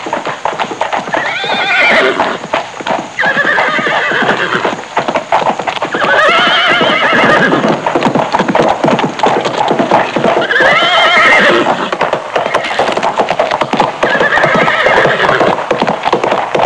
סוסים.mp3